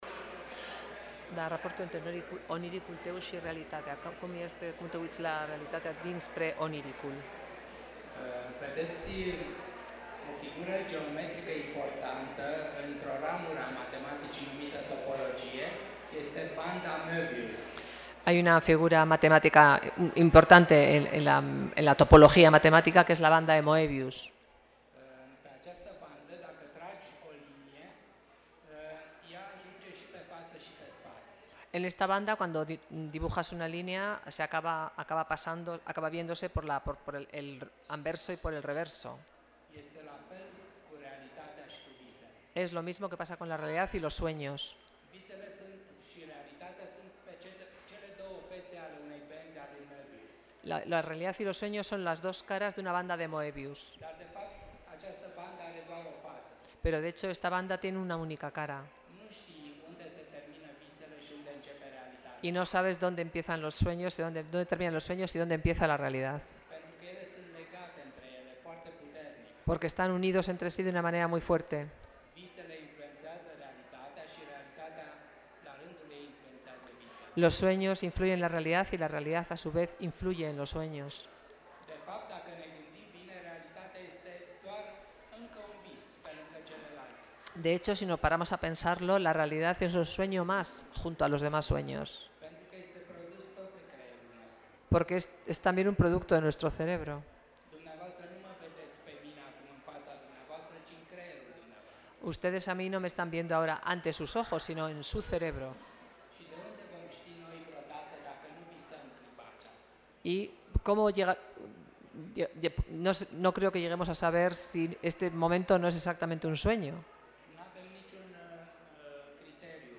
Respuesta de Mircea Cartarescu